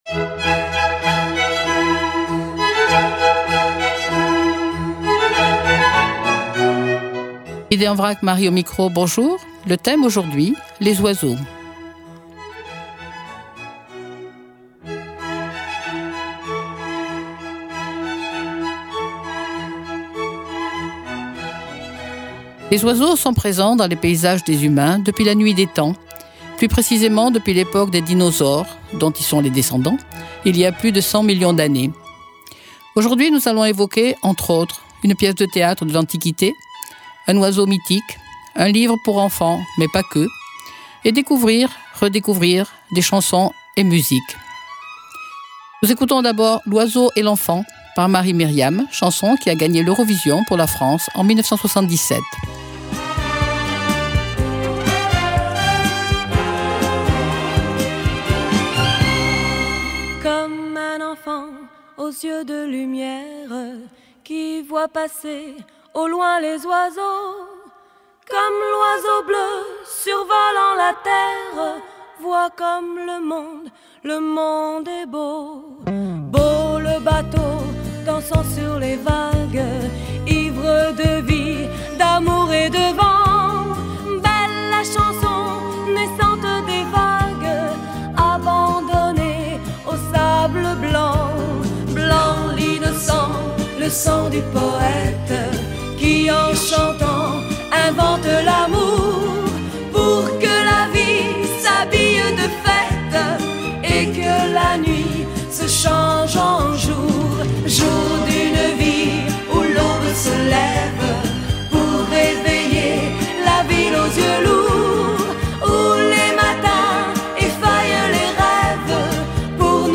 Émissions